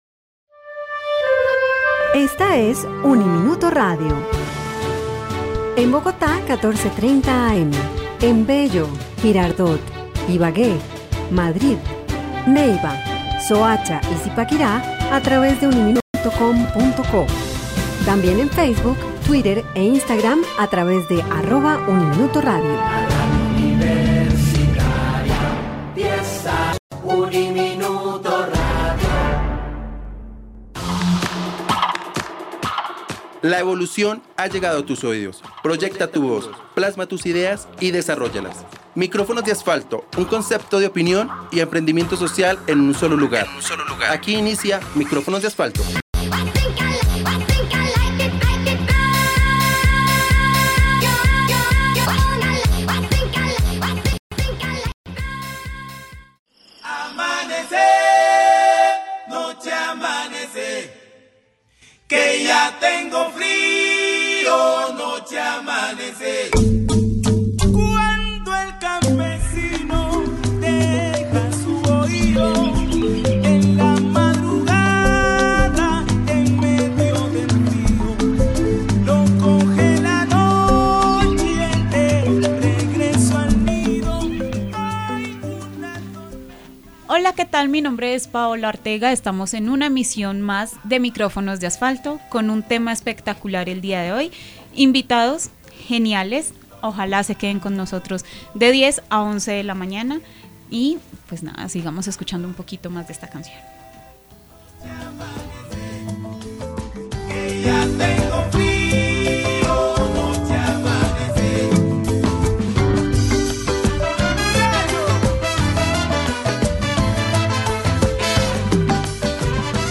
En esta emisión de Micrófonos de Asfalto, se entrevistara a un joven emprendedor de la localidad de Ciudad Bolívar, quien crece en el entorno del pandillismo y la drogadicción